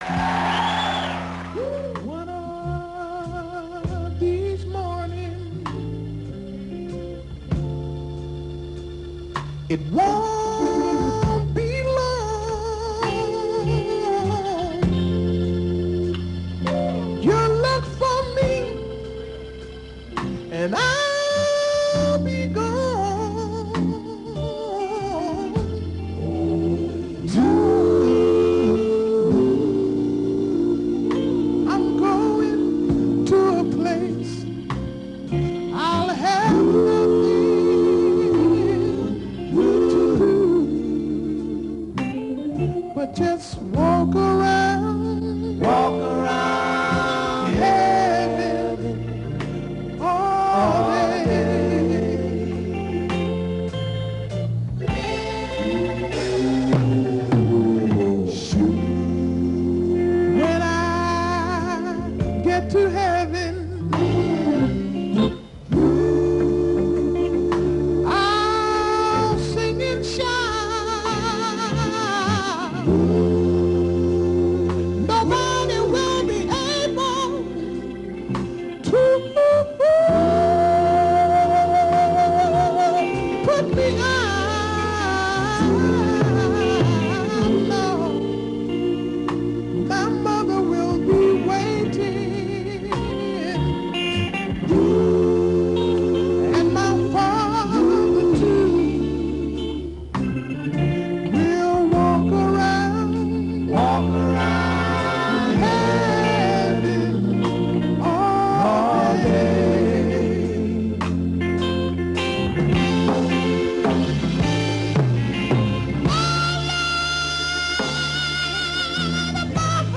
Montreux Jazz Festival 1981
organ